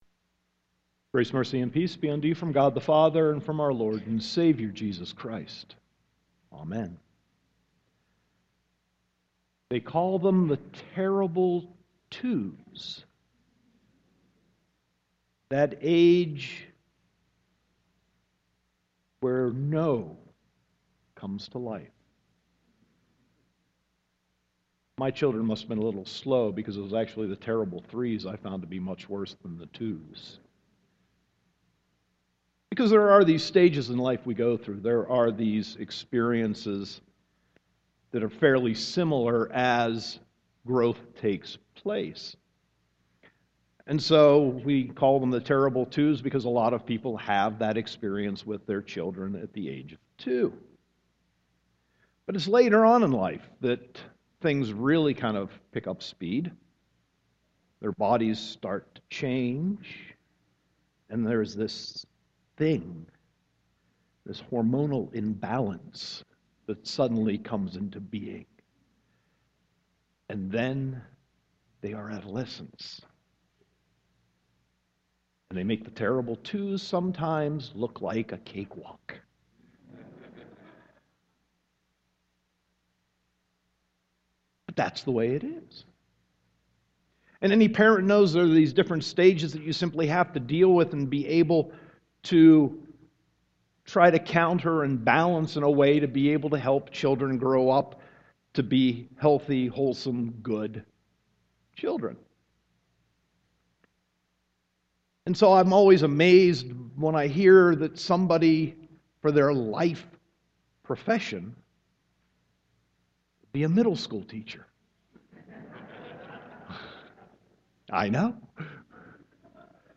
Sermon 5.31.2015